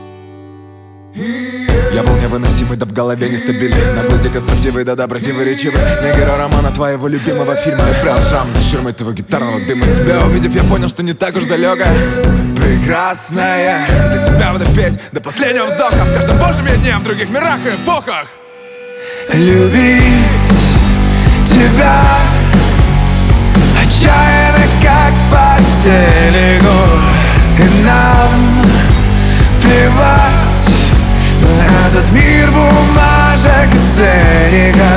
Pop Rock Pop